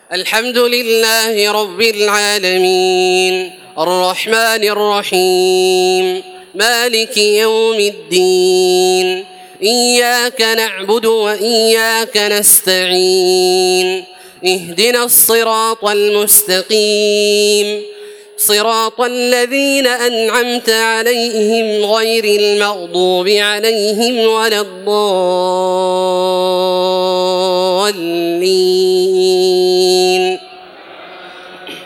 تحميل سورة الفاتحة بصوت تراويح الحرم المكي 1432
مرتل